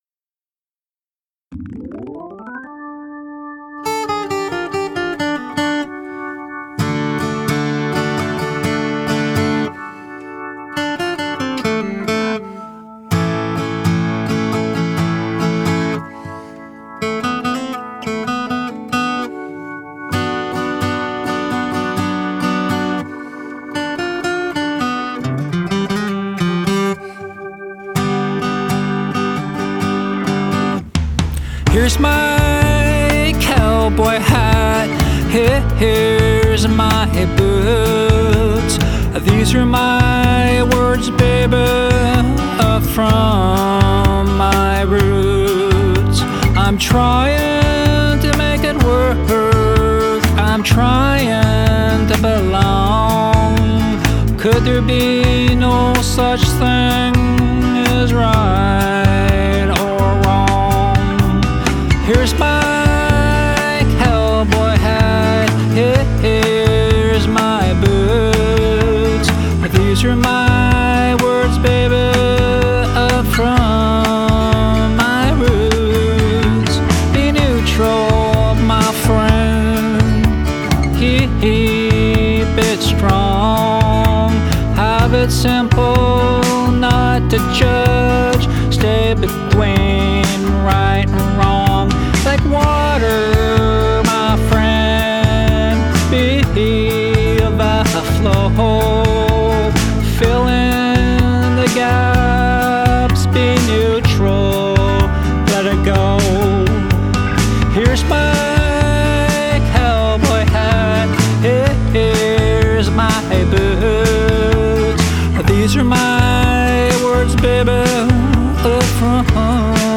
Canadian singer/songwriter